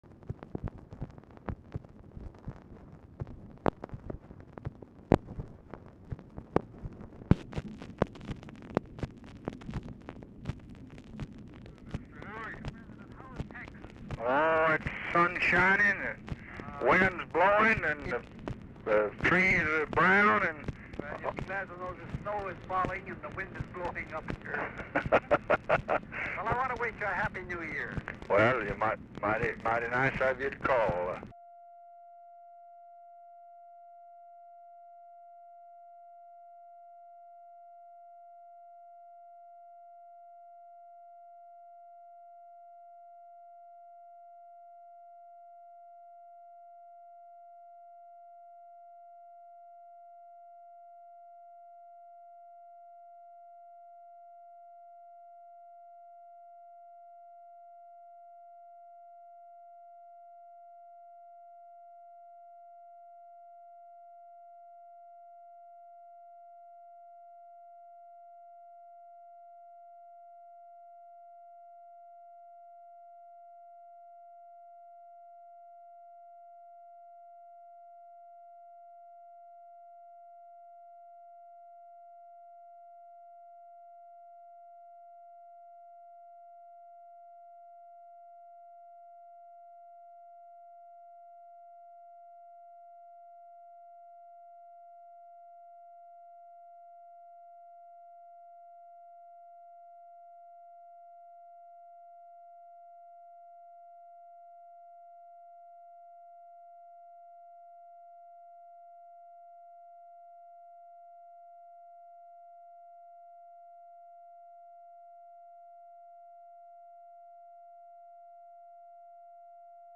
LBJ Ranch, near Stonewall, Texas
"PM OF CANADA"; PEARSON IS DIFFICULT TO HEAR
Telephone conversation
Dictation belt